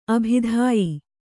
♪ abhidhāyi